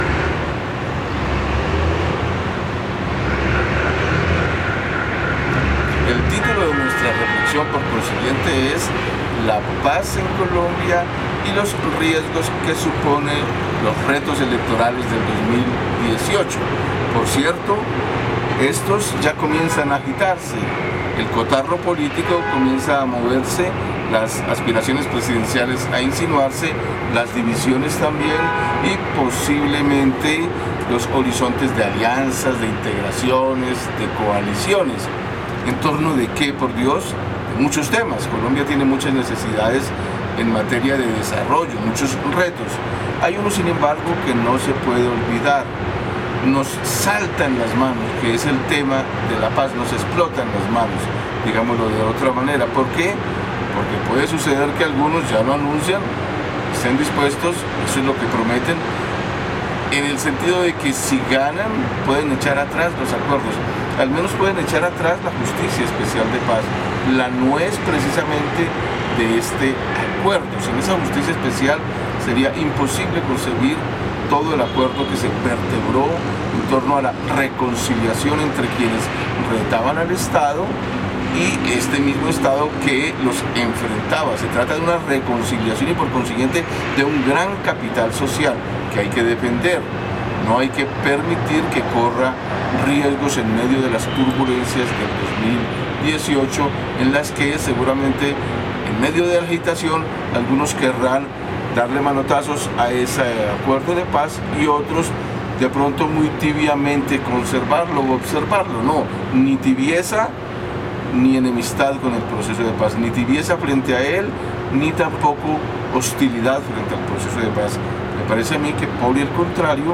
Informe radial